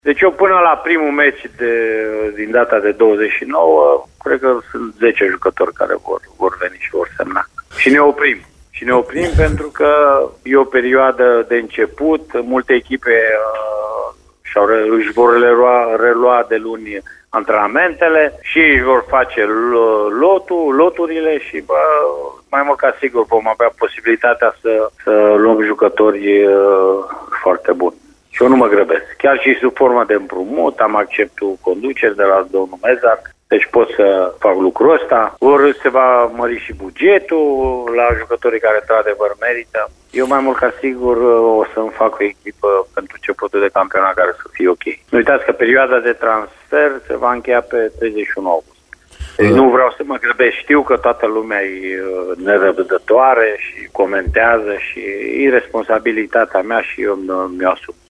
Într-o intervenție telefonică la Radio Timișoara, antrenorul Mircea Rednic a spus că așteaptă și alte întăriri și a precizat că următorii pe listă sunt un atacant olandez pe care îl cunoaște din Belgia și un fundaș, tratativele fiind în curs de finalizare.